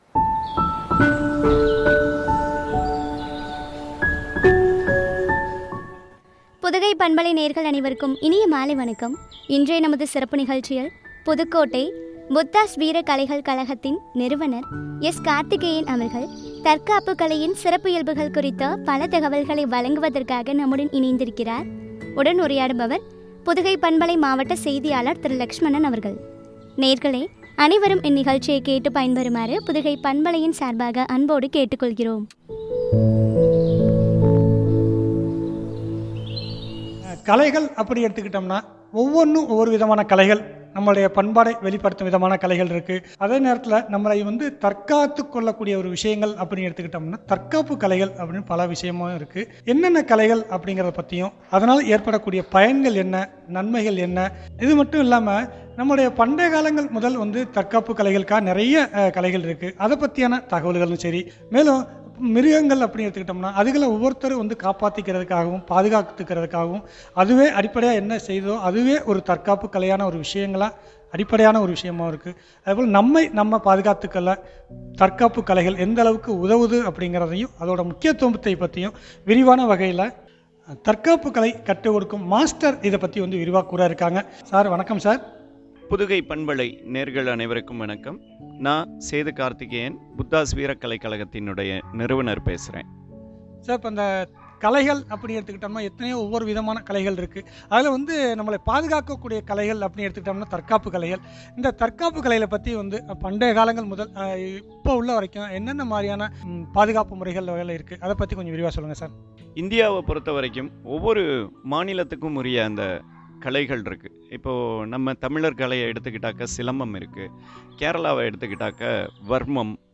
தற்காப்பு கலையின் சிறப்புகள் பற்றிய உரையாடல்.